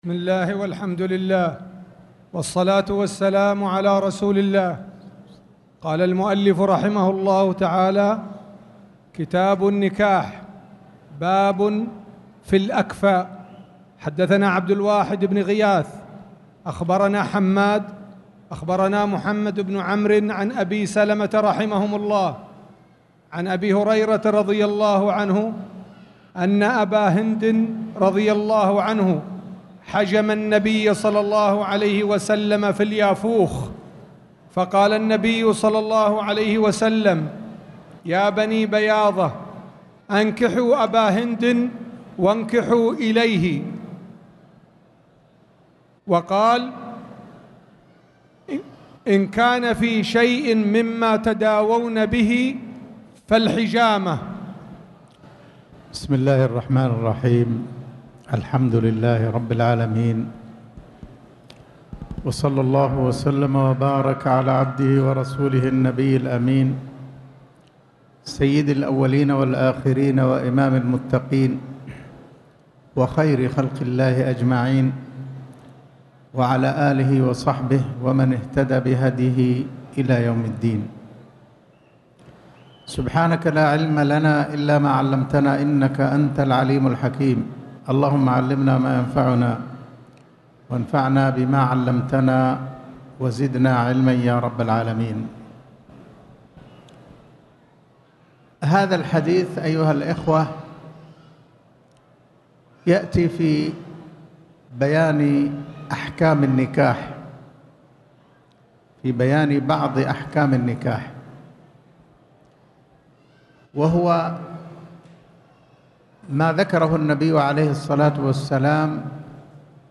تاريخ النشر ٢٦ محرم ١٤٣٨ المكان: المسجد الحرام الشيخ